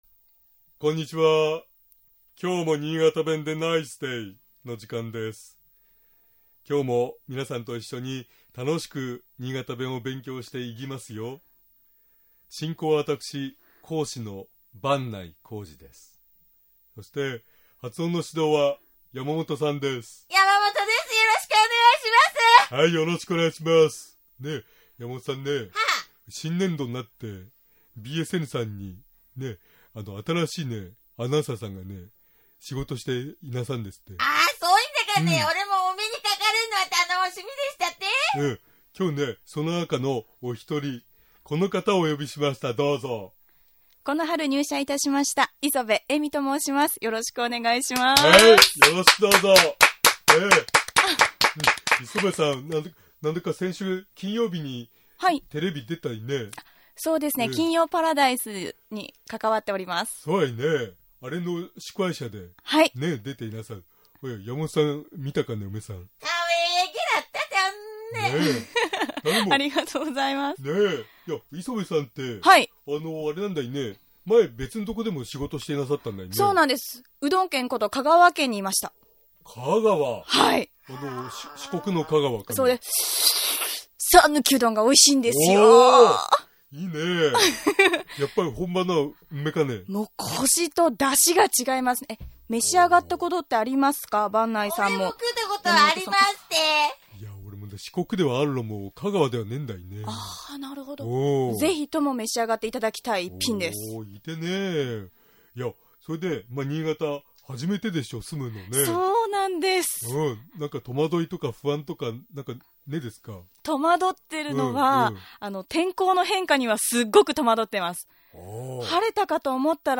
新潟弁では「え」が「い」に、「い」が「え」に聞こえる事が多々あります。